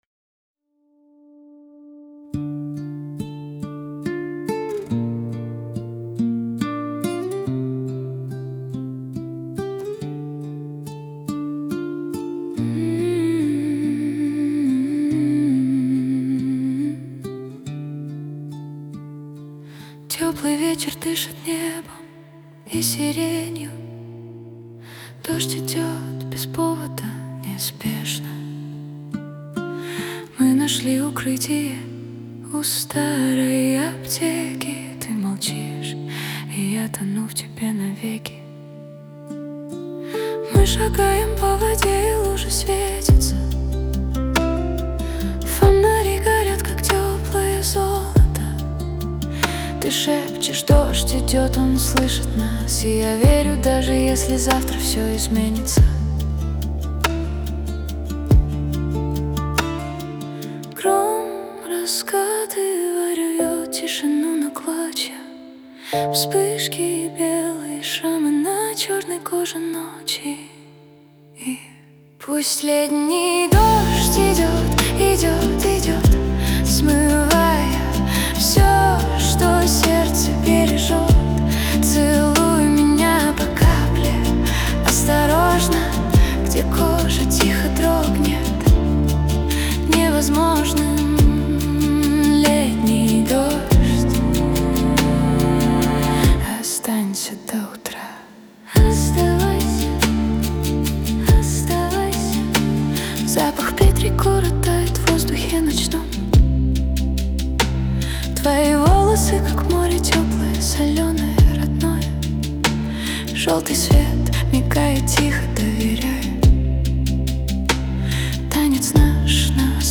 • Жанр: Поп